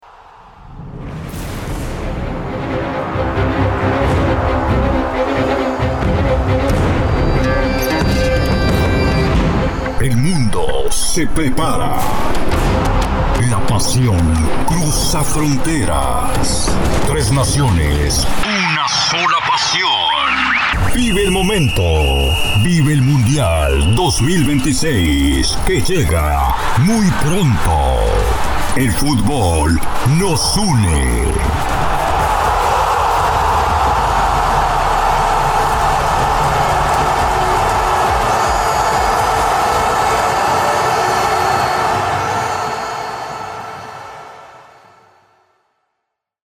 All our voice actors are premium seasoned professionals.
Adult (30-50)